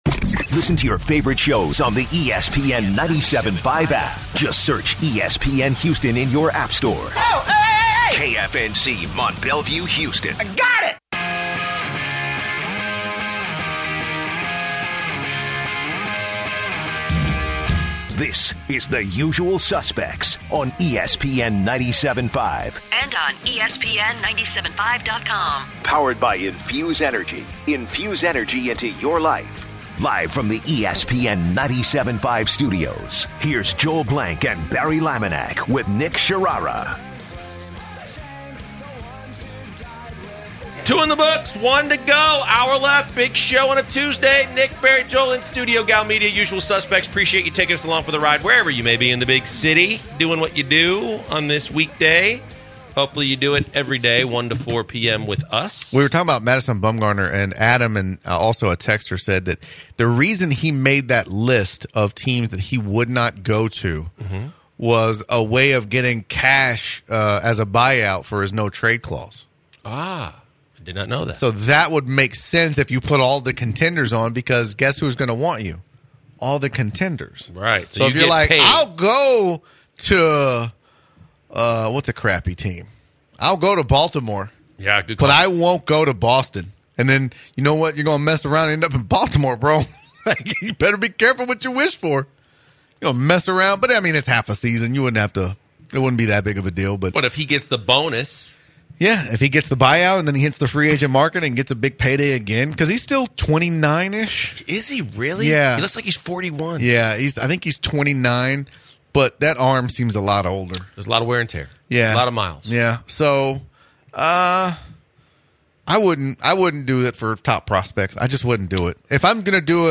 The guys start the final hour talking about the Astros and the owners of the team. They take a caller and are talk about Tyler White.